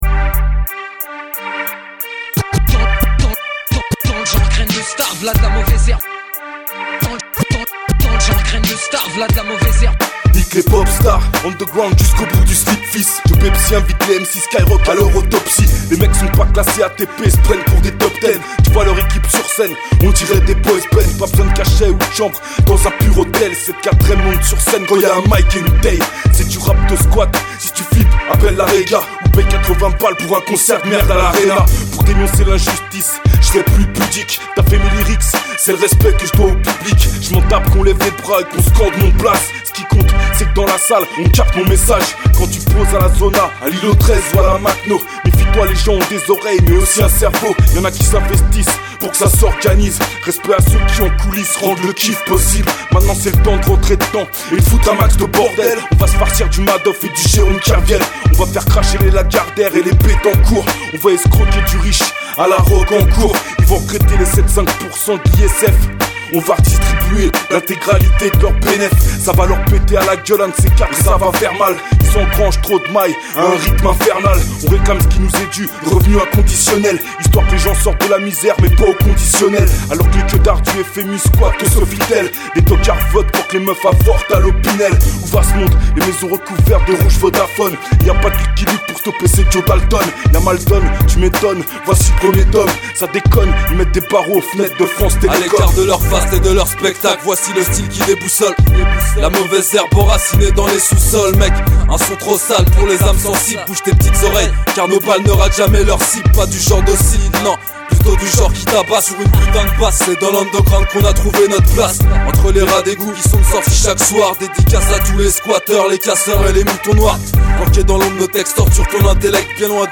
scratch